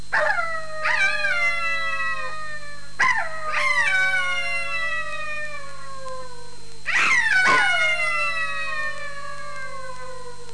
wolf12.mp3